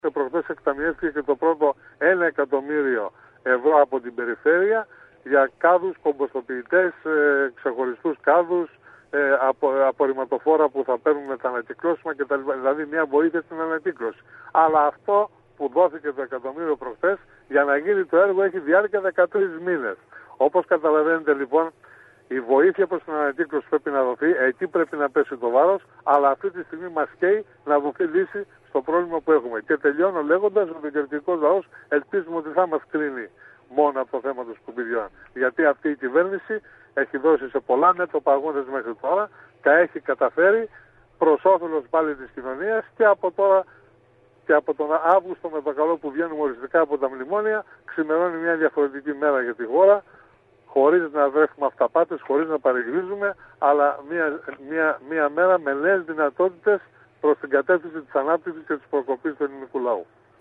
απόσπασμα των πολιτικών δηλώσεων